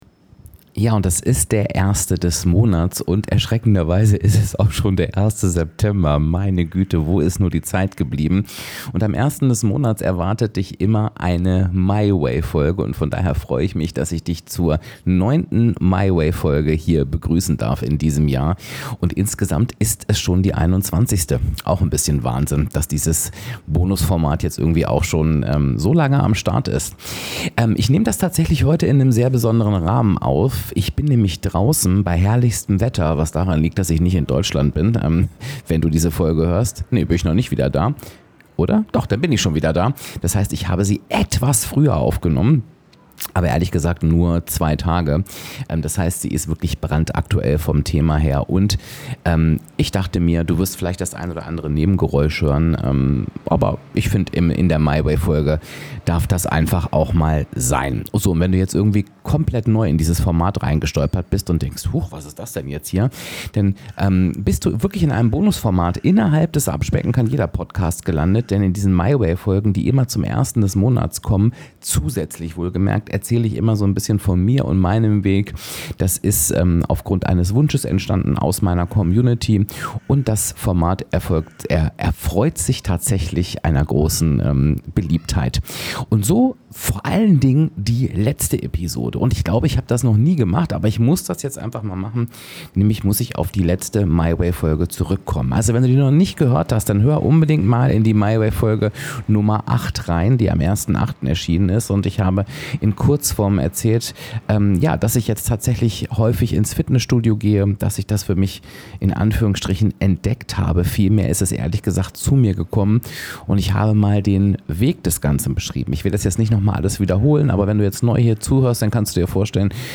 Ehrlich & direkt – und warum Kontinuität deinen Erfolg bestimmt Neuer Monat, neue My Way-Folge: Ich nehme dich mit in meinen September-Start: Draußen aufgenommen, brandaktuell, ein bisschen „on the road“-Atmosphäre inklusive.